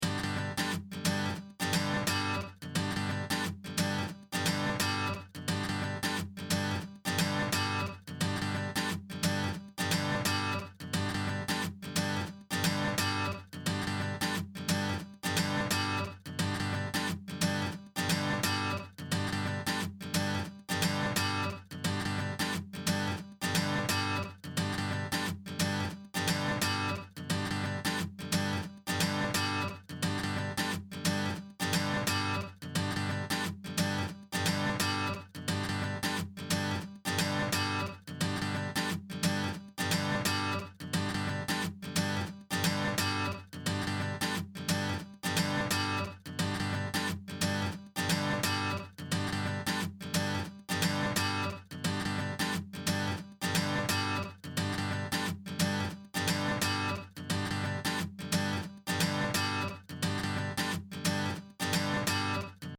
heyguitar.mp3